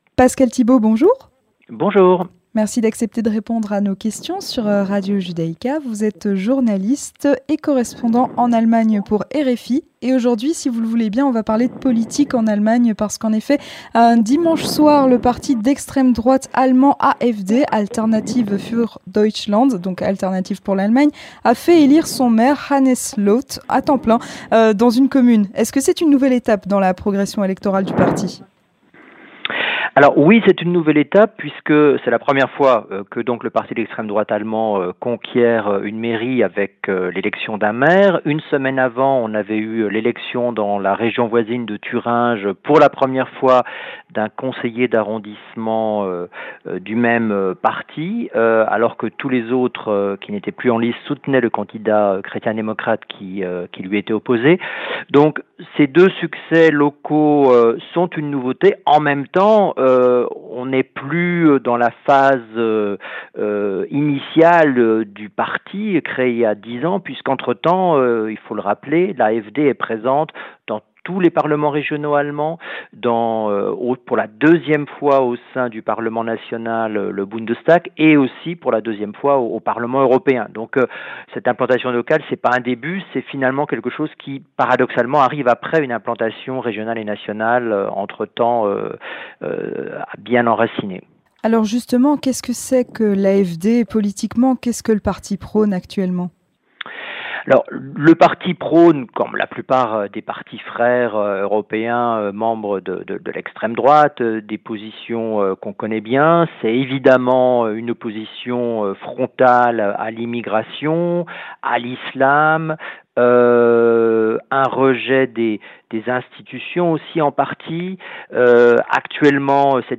Entretien du 18h - L'extrême droite en Allemagne